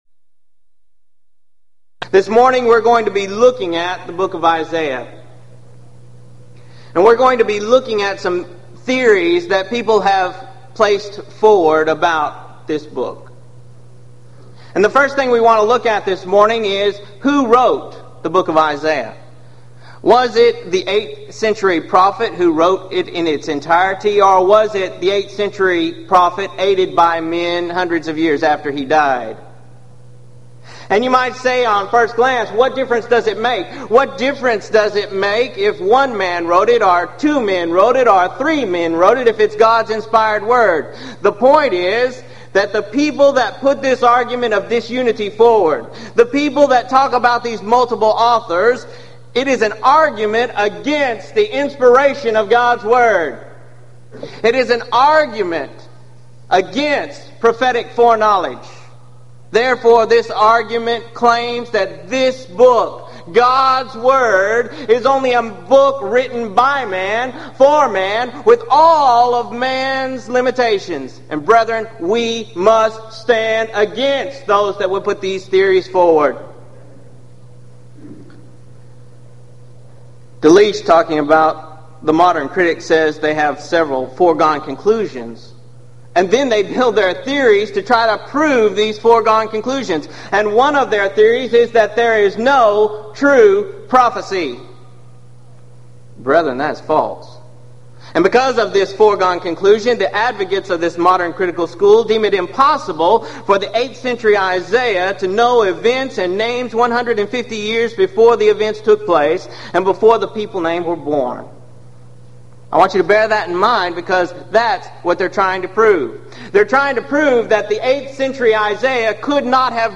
Event: 1995 Gulf Coast Lectures
lecture